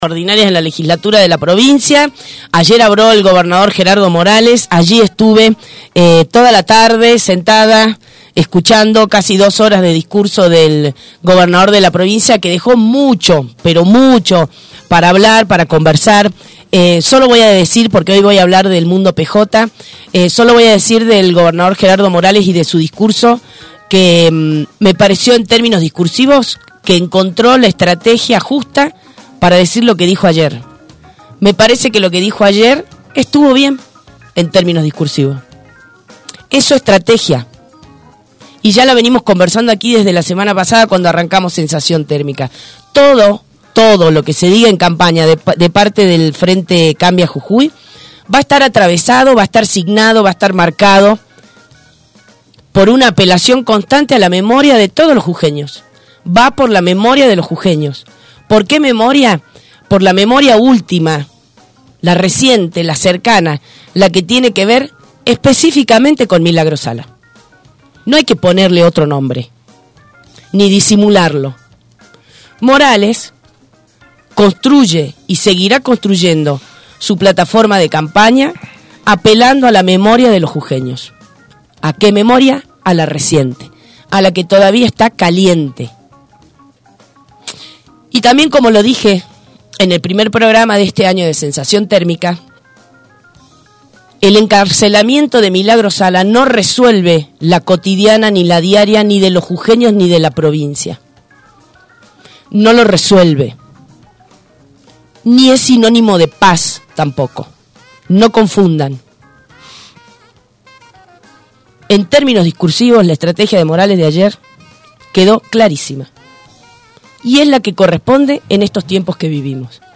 El candidato a gobernador para las próximas elecciones por Unidad Ciudadana, el peronista Adrián Mendieta, en entrevista con “Sensación Térmica”, fue lapidario con algunos sectores justicialista.